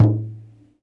萨满教鼓（buben） " Buben1
描述：一个萨满鼓（buben）的样本，大约15年前在Kurgan镇的西西伯利亚录制。